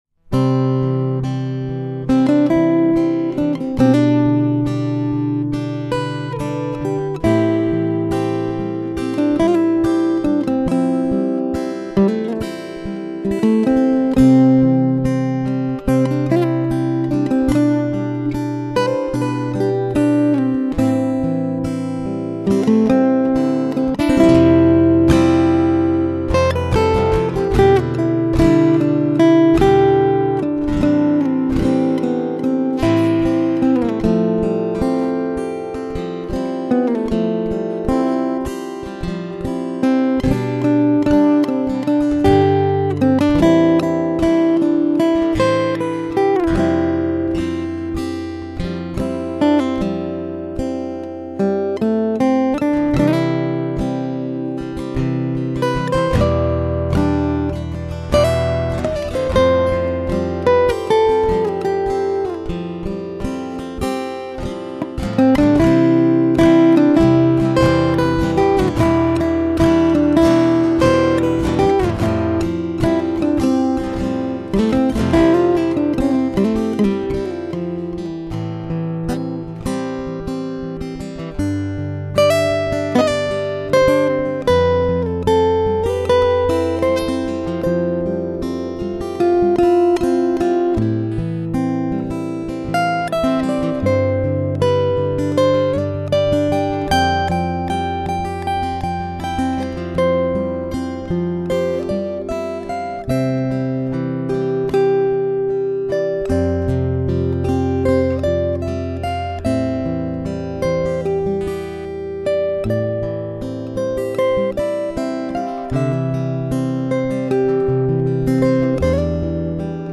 Suara Burung Merdu Dan Unik Sound Effects Free Download